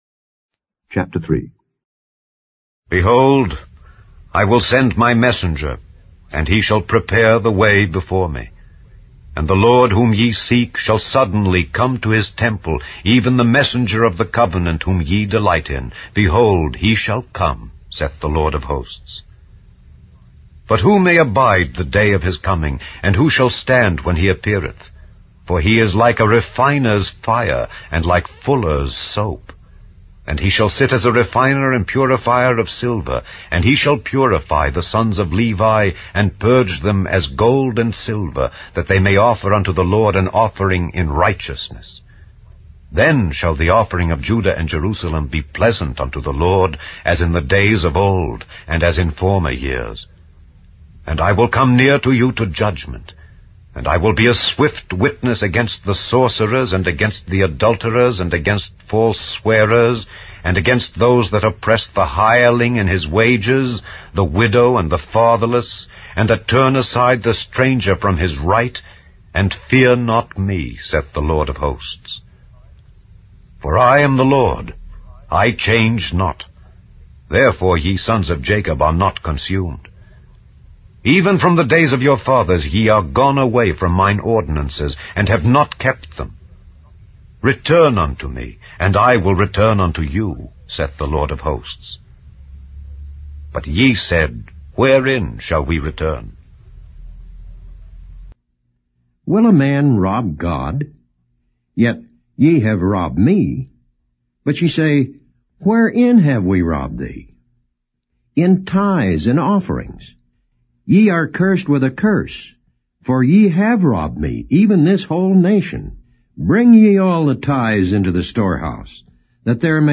English Audio Bible - Malachi 4 in Ervmr bible version